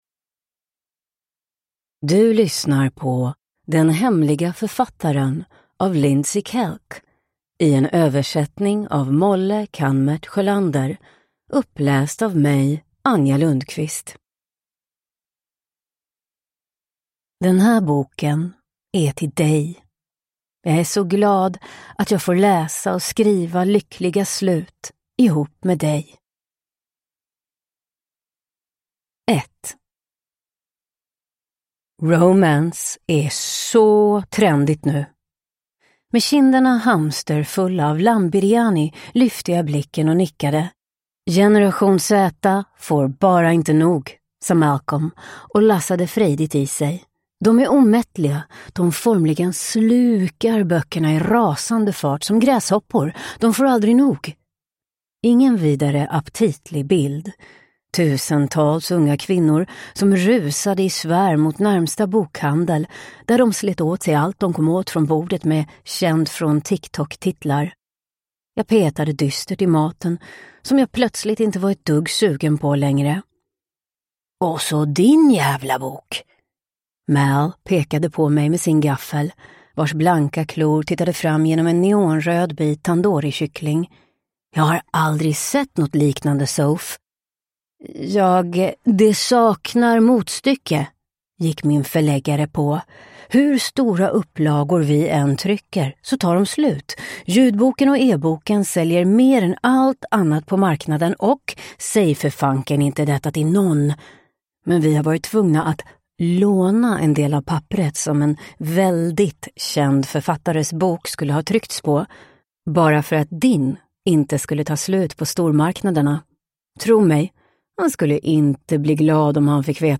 Den hemliga författaren – Ljudbok